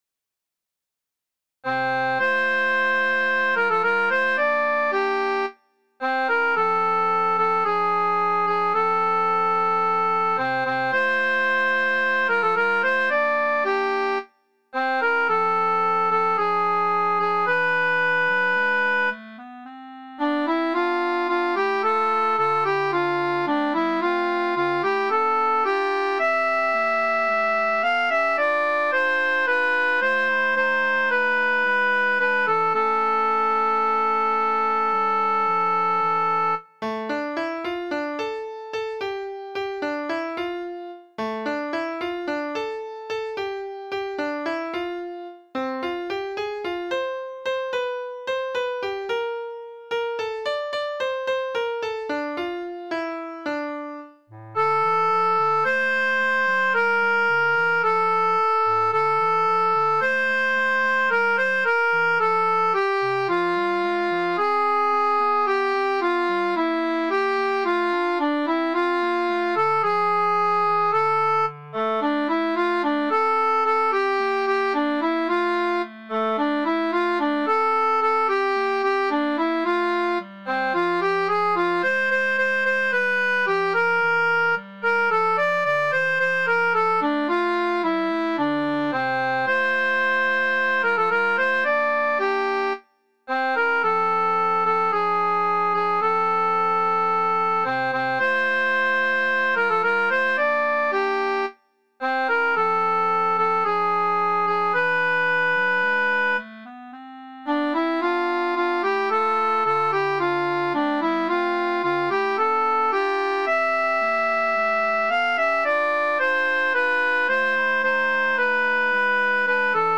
soprano alto
siman_she_od_lo_soprano.mp3